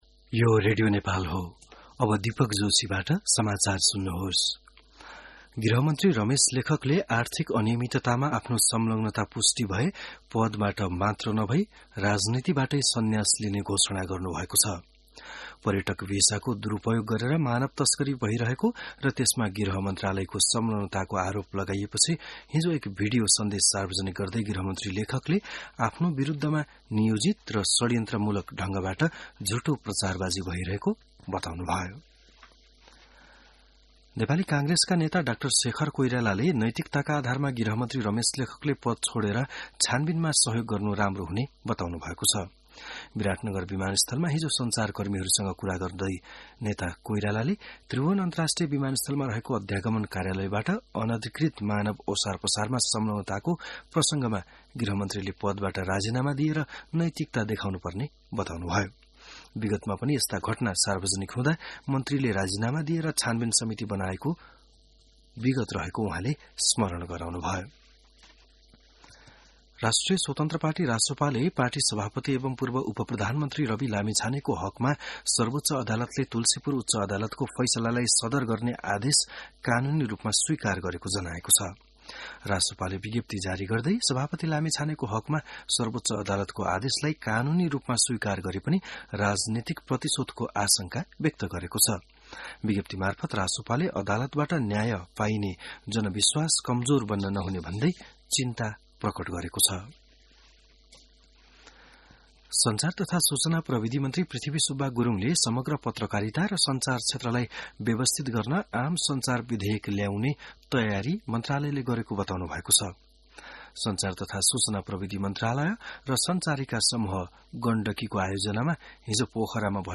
बिहान १० बजेको नेपाली समाचार : ११ जेठ , २०८२